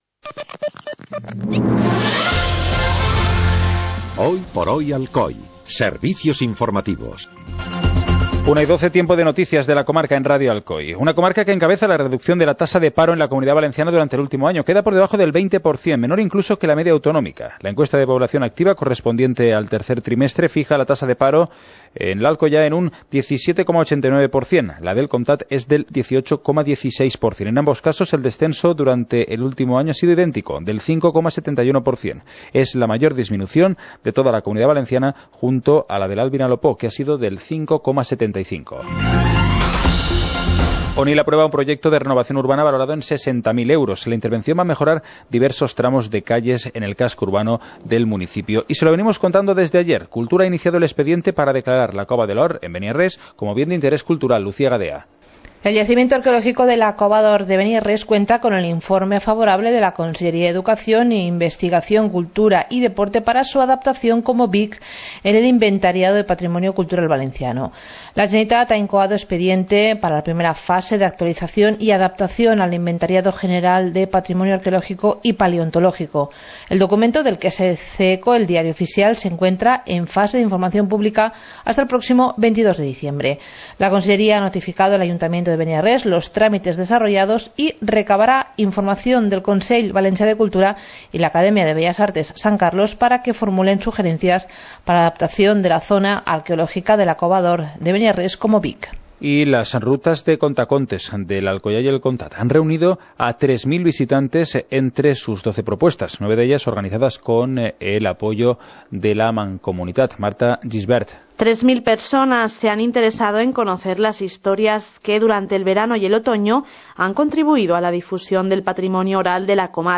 Informativo comarcal - miércoles, 23 de noviembre de 2016